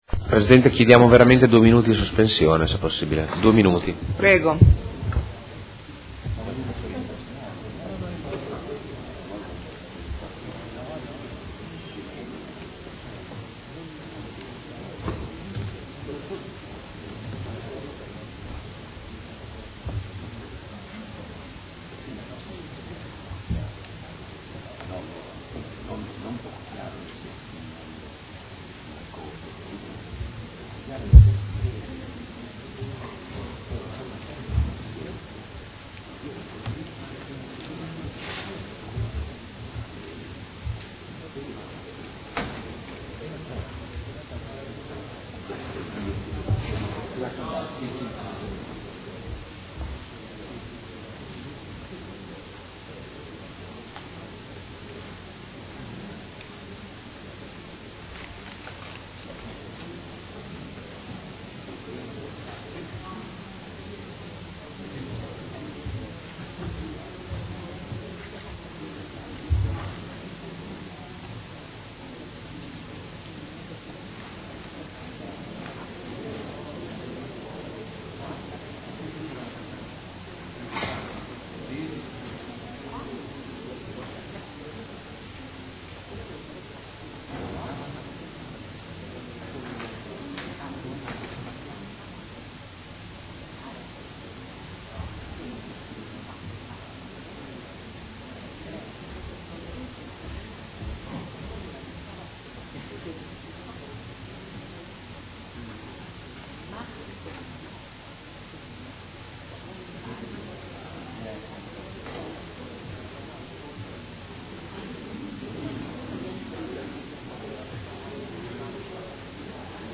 Luca Fantoni — Sito Audio Consiglio Comunale
Seduta del 14/01/2016. Chiede sospensione